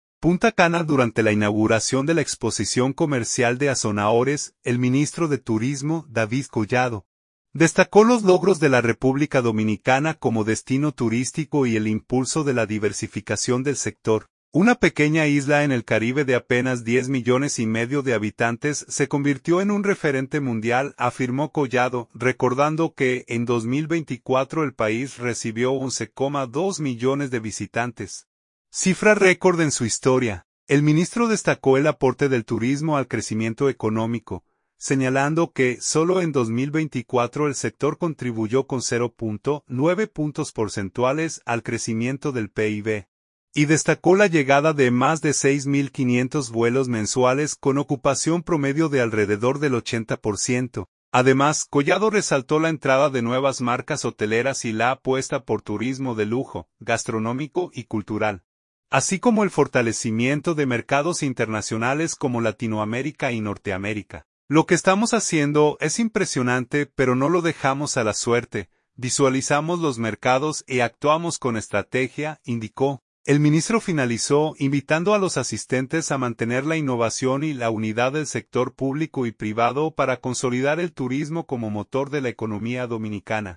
Punta Cana.– Durante la inauguración de la Exposición Comercial de Asonahores, el ministro de Turismo, David Collado, destacó los logros de la República Dominicana como destino turístico y el impulso de la diversificación del sector.